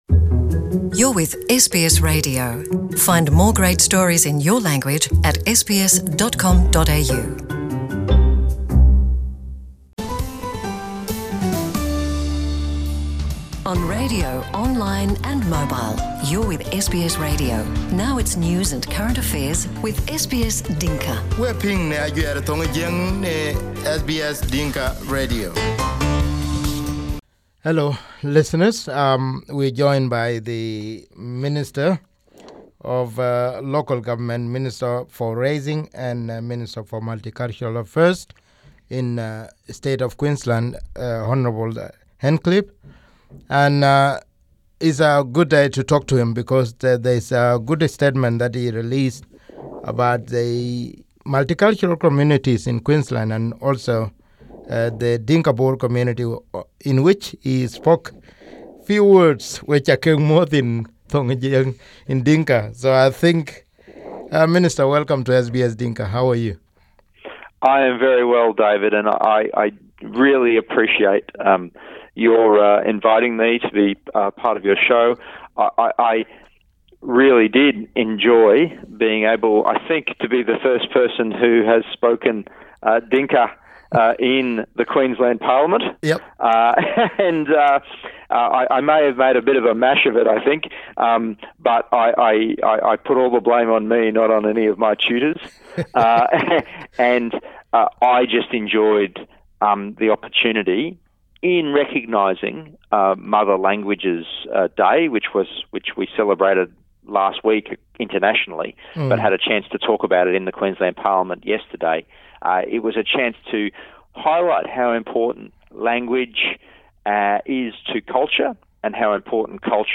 SBS Dinka interviewed Minister Stirling Hinchliffe in February and this interview was kept to be broadcast in March.